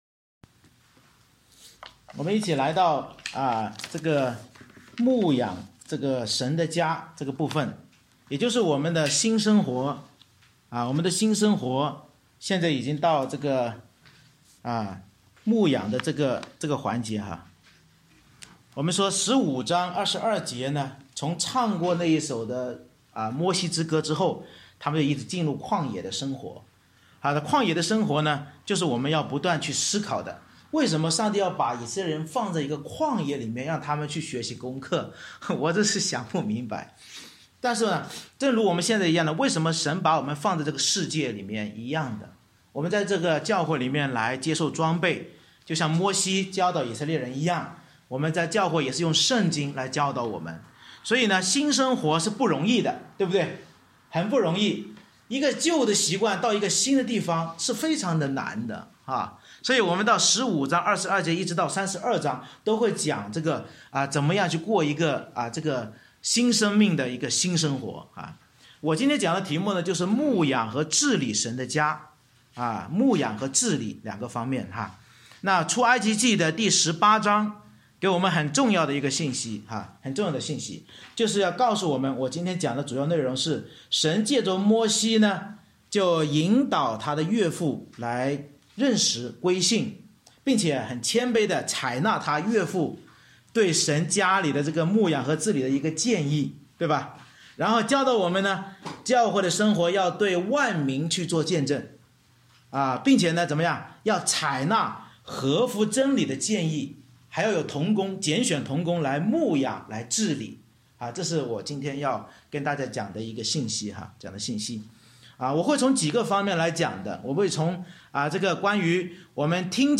牧养治理神的家 Series: 《出埃及记》讲道系列 Passage: 出埃及记18章 Service Type: 主日崇拜 Bible Text